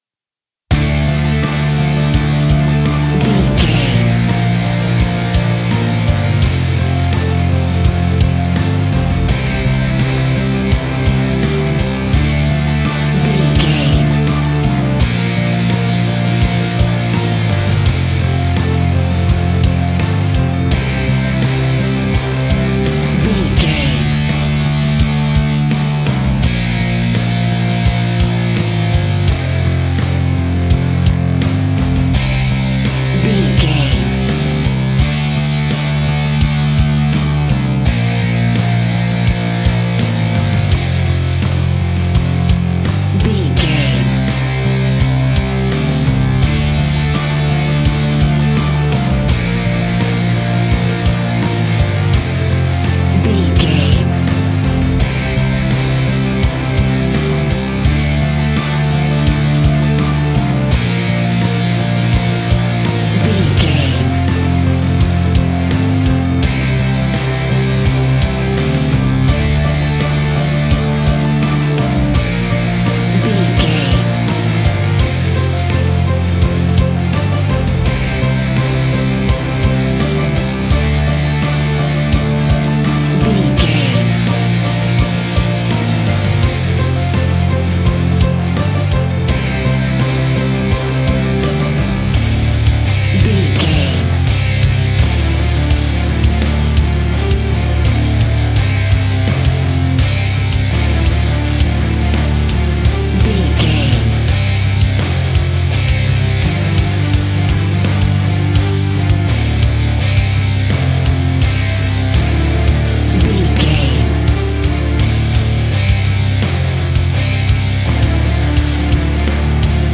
Epic / Action
Fast paced
Aeolian/Minor
D
hard rock
heavy metal
scary rock
instrumentals
Heavy Metal Guitars
Metal Drums
Heavy Bass Guitars